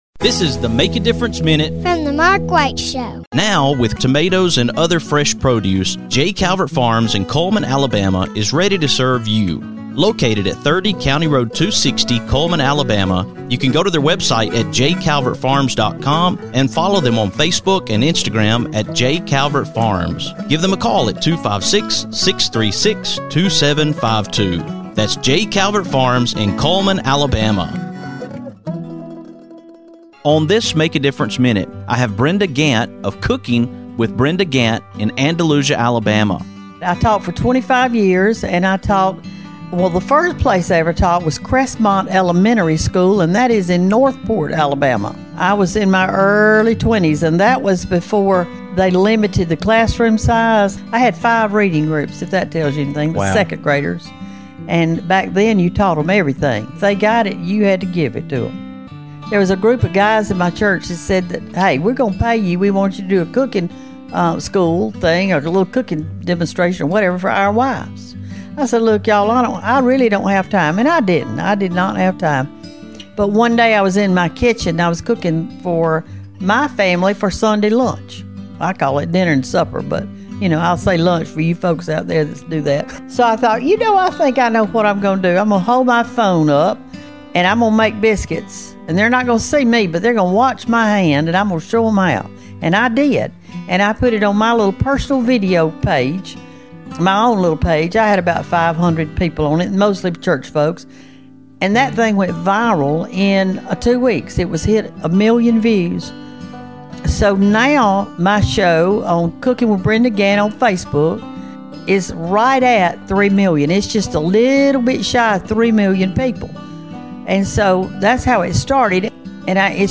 On this MADM, I am taking you to Andalusia, Alabama, to the home of Brenda Gantt of Cooking with Brenda Gantt. Brenda shares about her work as a teacher and how Brenda Gantt became a household name.